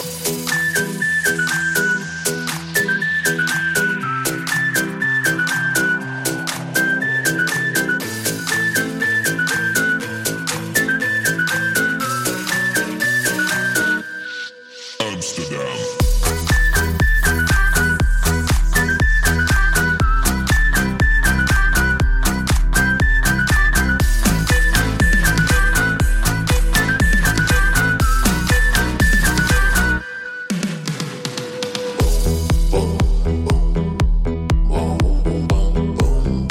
• Качество: 128, Stereo
свист
ритмичные
громкие
зажигательные
веселые
Dance Pop
house
Интересная мелодия на звонок, практически без слов)